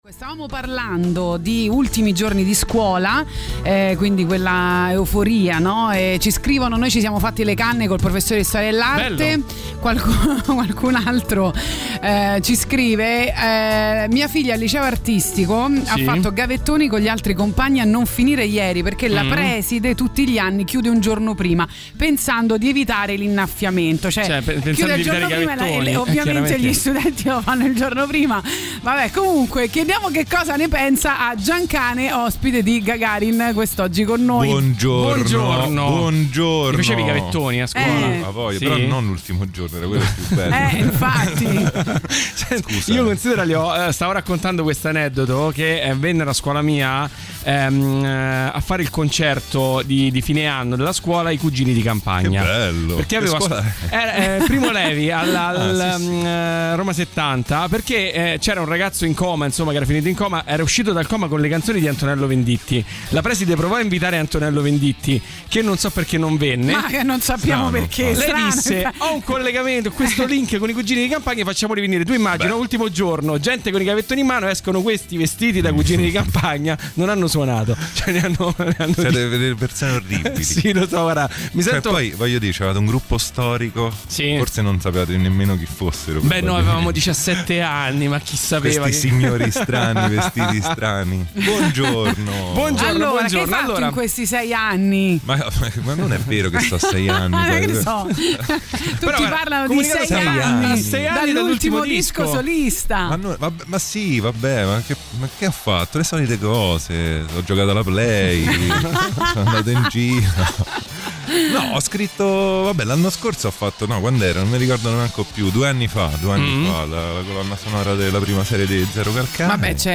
Interviste: Giancane (08-06-23)